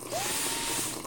vacuum-cleaner-fast.ogg